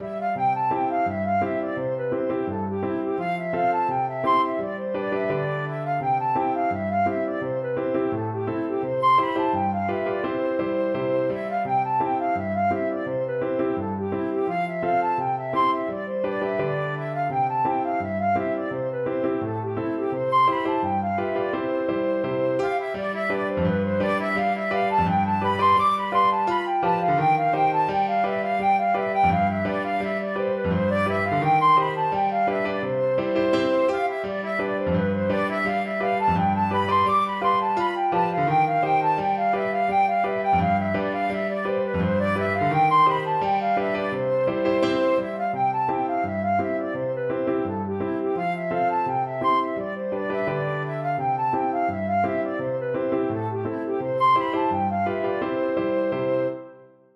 Flute
Traditional Music of unknown author.
C major (Sounding Pitch) (View more C major Music for Flute )
2/4 (View more 2/4 Music)
Molto Moderato = c. 84